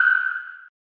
sonar3.mp3